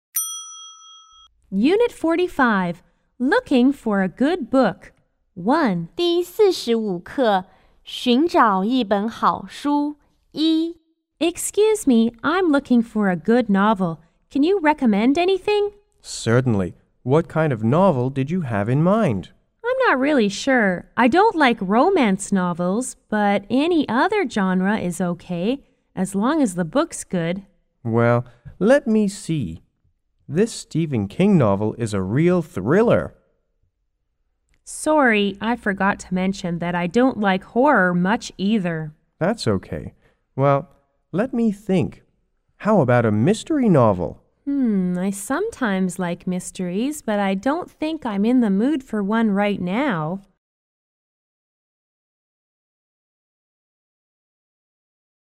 C= Customer S= Salesperson